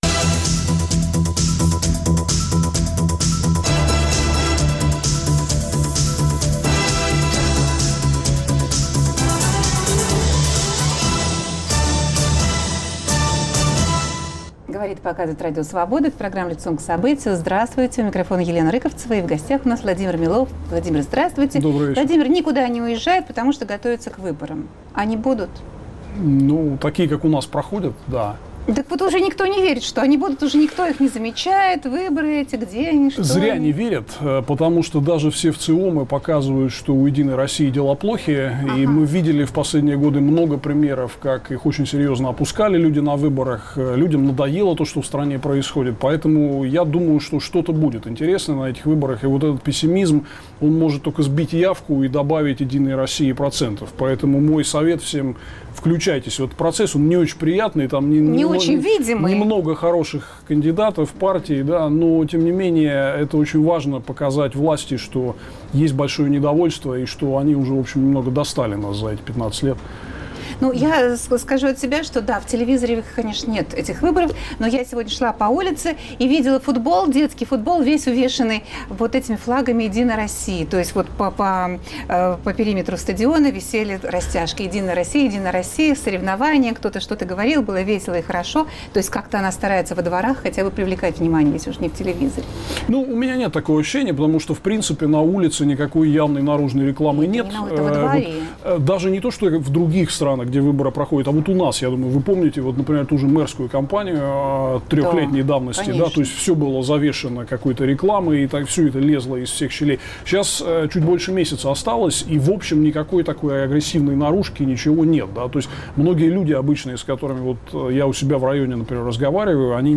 Гость студии Владимир Милов.